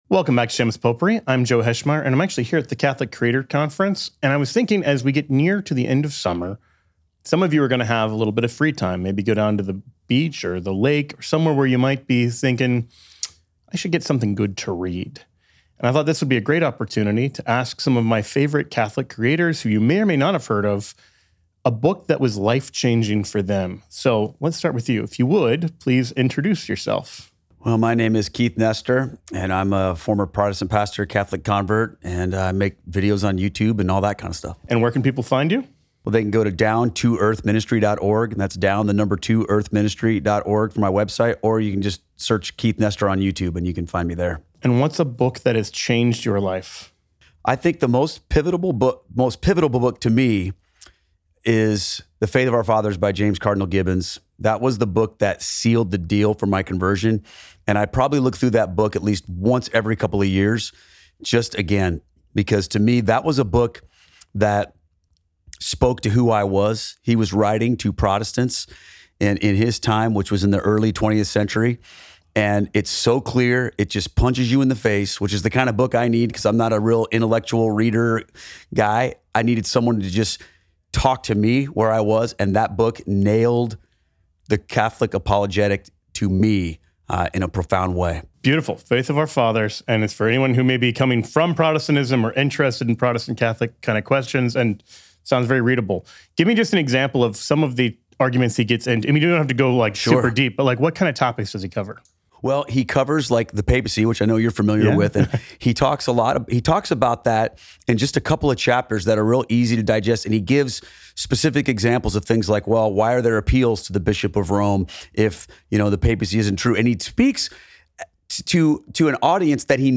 Catholic Creator Conference